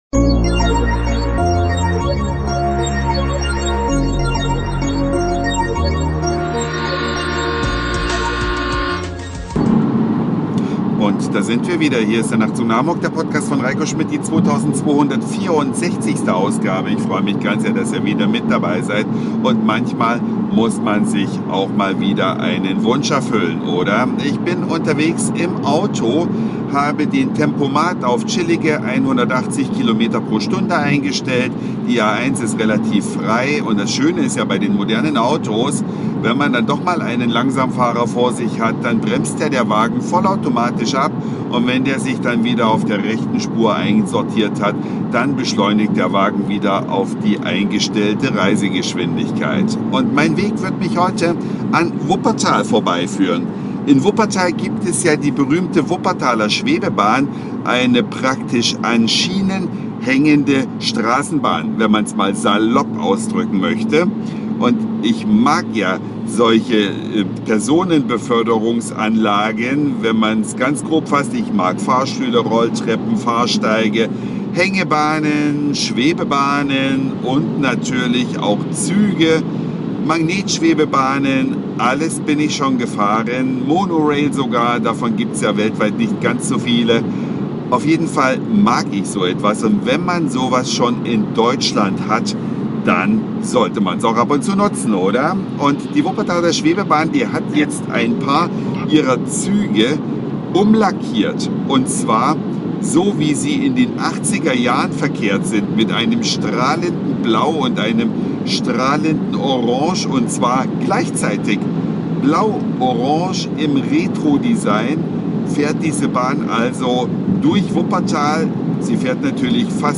125 Jahre alt und einzigartig, unter Denkmalschutz: eine Fahrt mit der Wuppertaler Schwebebahn, die als Nahverkehrsmittel durch ganz Wuppertal fährt